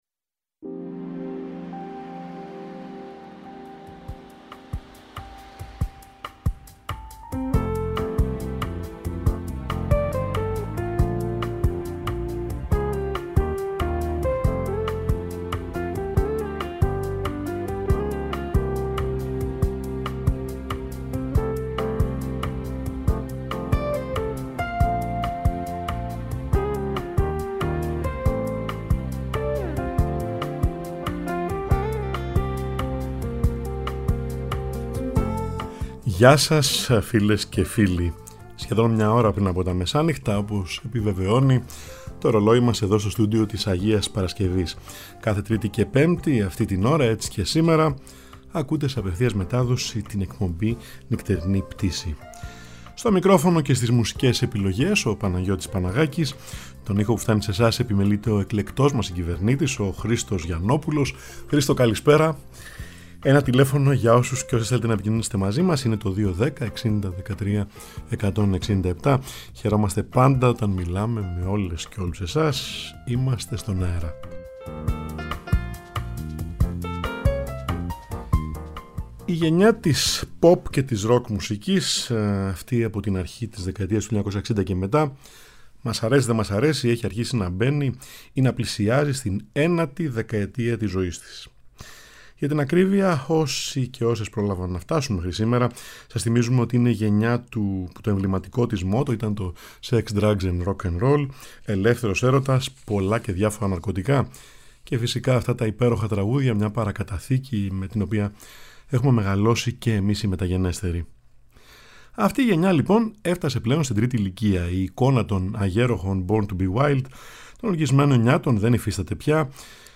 Μια σπουδαία τραγουδοποιός μπαίνει σήμερα στην ένατη δεκαετία της ζωής της. Γνωστά τραγούδια από την ίδια και διάσημους μουσικούς της jazz.
Στη «Νυχτερινή Πτήση» που απογειώνεται κάθε Τρίτη & Πέμπτη μία ώρα πριν από τα μεσάνυχτα, στο Τρίτο Πρόγραμμα 90,9 & 95,6 της Ελληνικής Ραδιοφωνίας